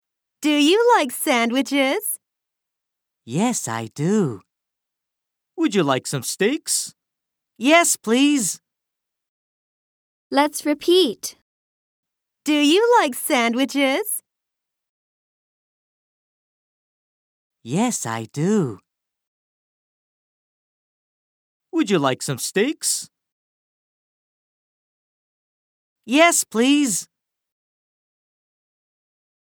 場面下のダイアログ
aj1_19_s3_dialog.mp3